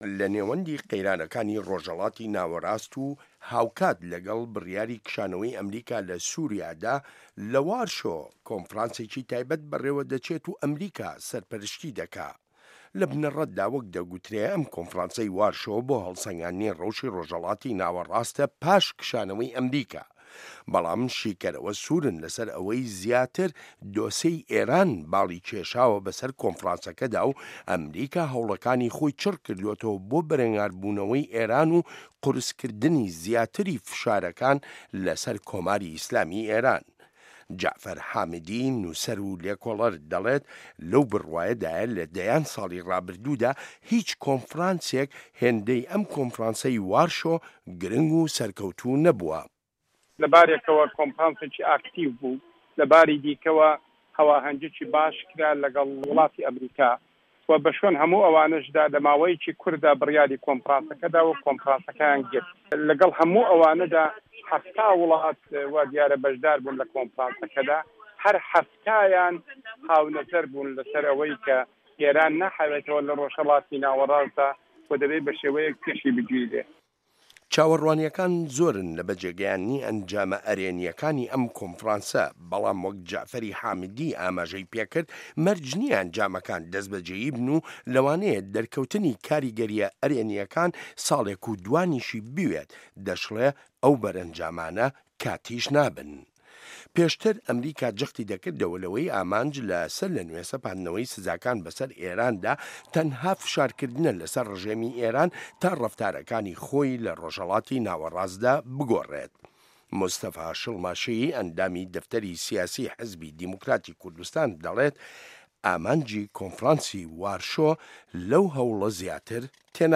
ڕاپۆرتی کۆنفرانسی وارشۆ لە دیدی سیاسییەکانی ڕۆژهەڵاتی کوردستانەوە